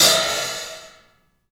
Index of /90_sSampleCDs/E-MU Producer Series Vol. 5 – 3-D Audio Collection/3DPercussives/3DPAHat